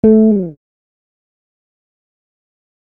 A HI FALL.wav